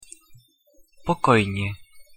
pronunciation_sk_pokojne.mp3